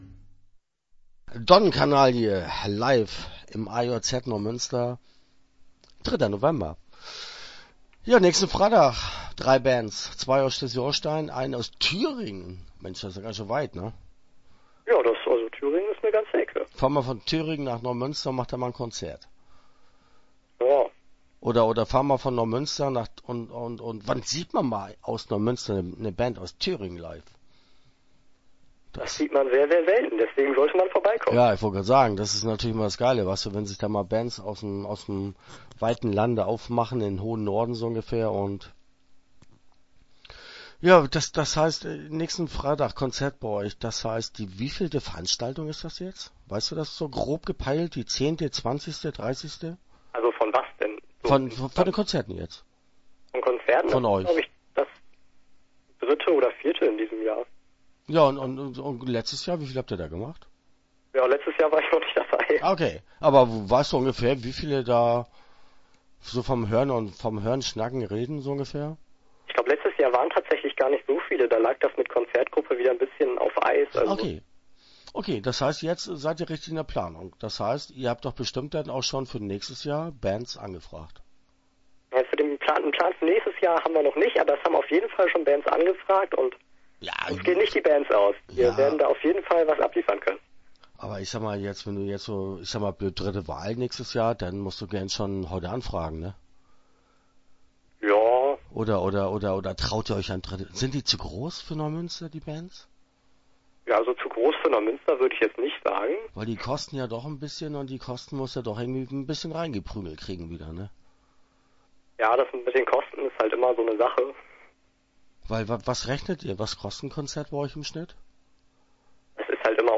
Start » Interviews » Konzertgruppe AJZ Neumünster